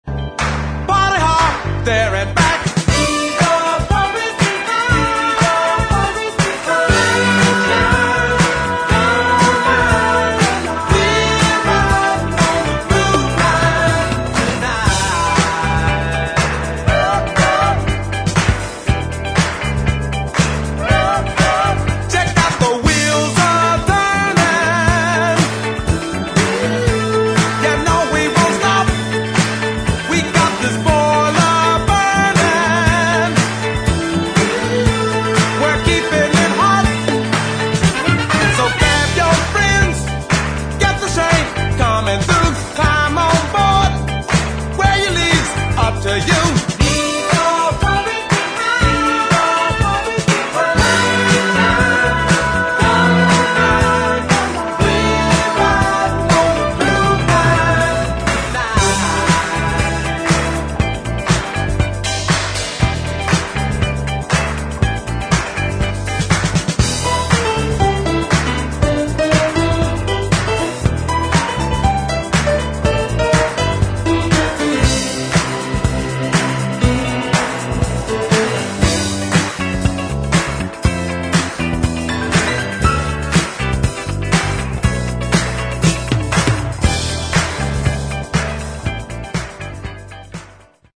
[ DISCO / FUNK ]